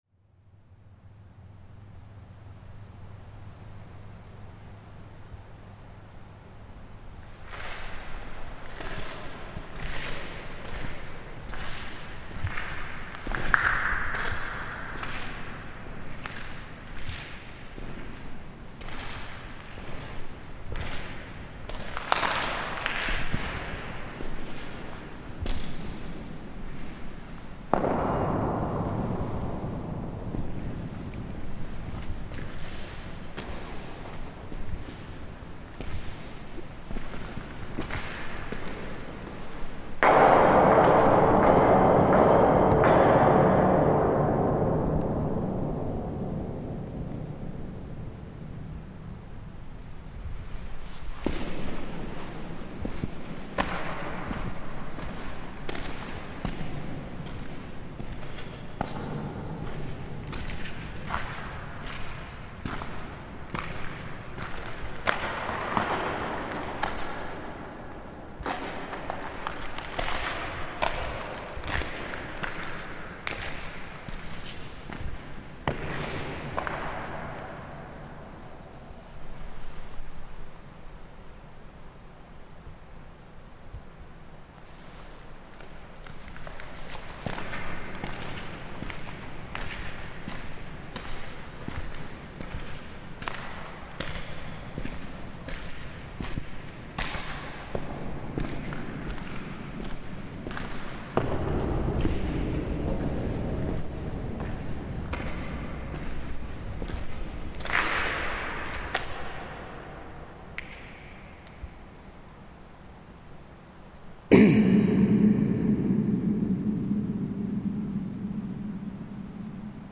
Inside the Djúpavík oil tank, Iceland - a huge reverb, cavernous footsteps and a recording of singing inside the tank.